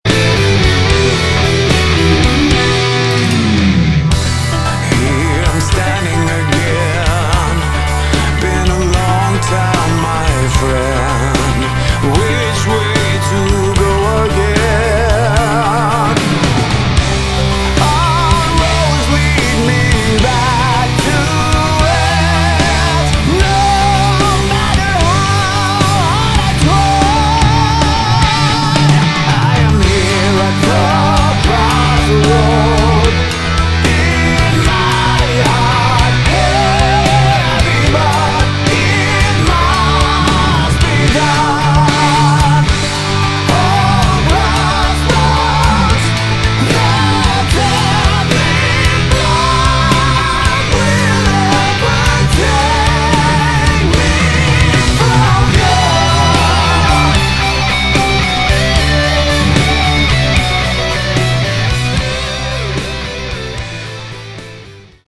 Category: Melodic Rock / AOR
guitar, bass, synthesizer, backing vocals
drums, percussion
keyboards